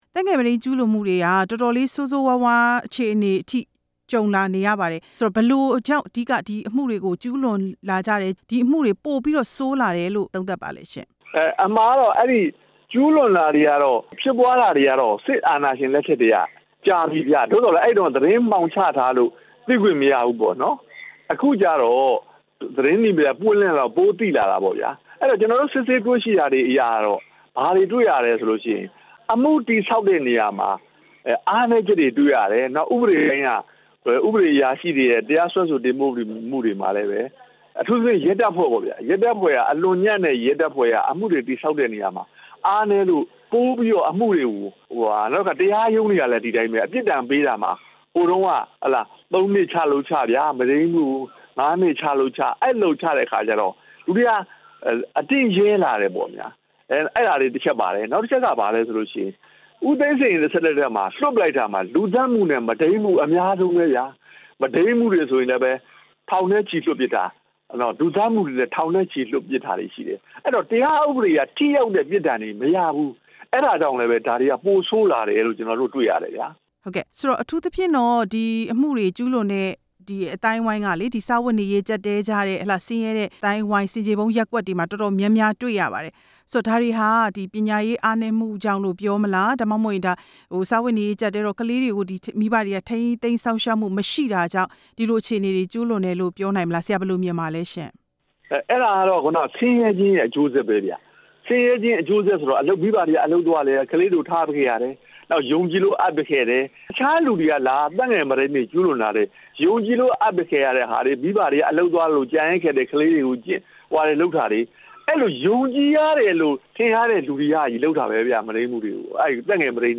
တရားလွှတ်တော်ရှေ့နေ